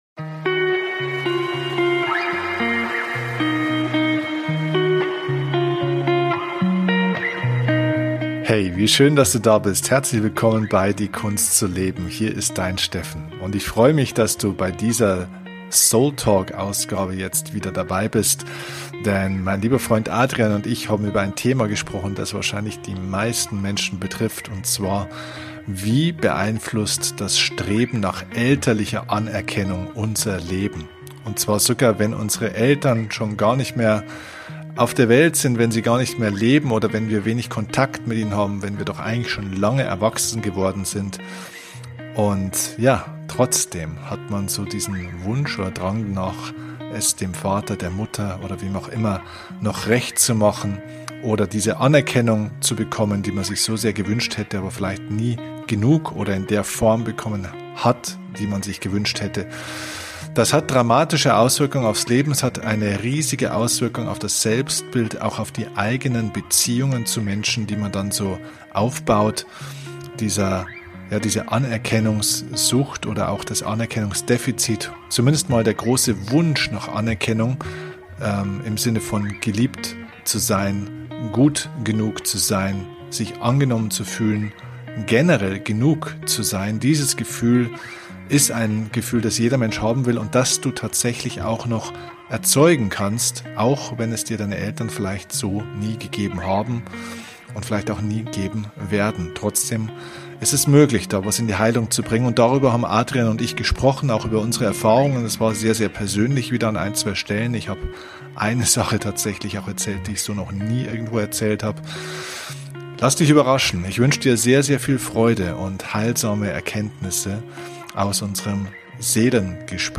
Wie immer bei diesem Format gibt es kein Skript